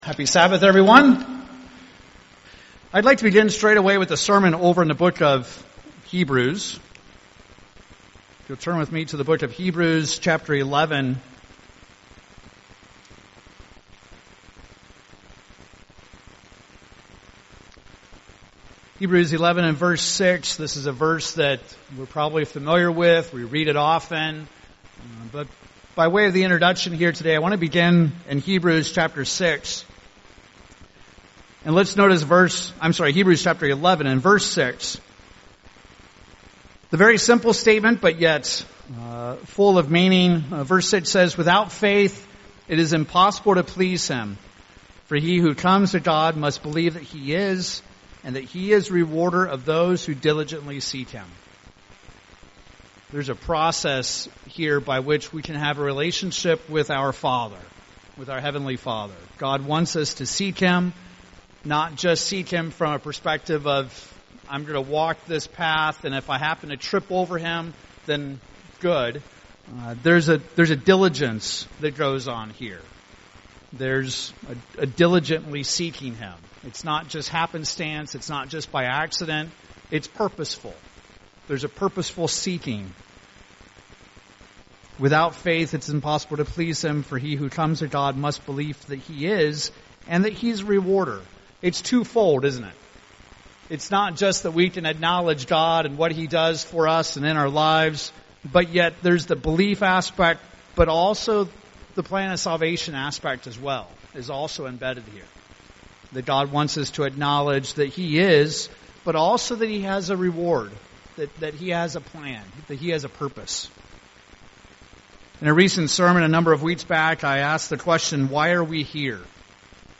In this sermon, we examine many theories of the existence of the universe and views of god. Society has lost track of the real Biblical God and confuses Him with concepts of Roman and Greek gods, creation worship, and even philosophies like fung shei and horoscopes.